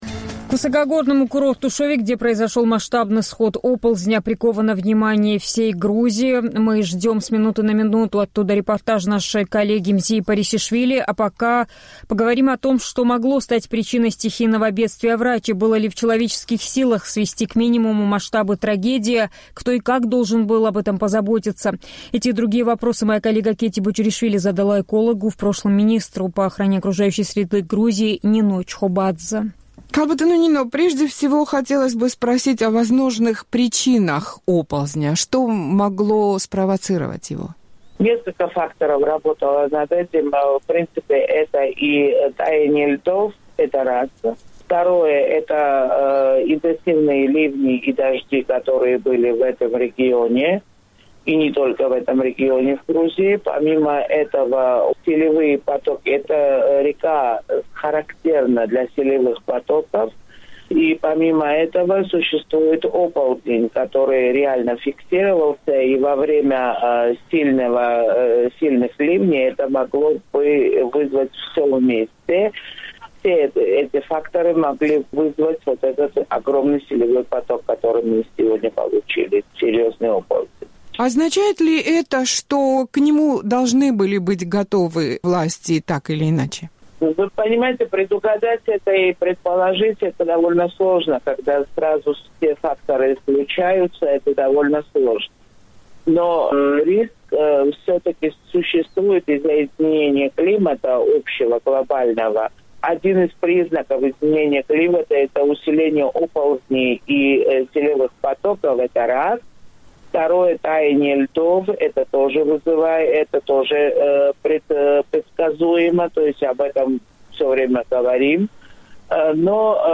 Об этом «Эхо Кавказа» поговорило с экологом, в прошлом – грузинским министром по охране...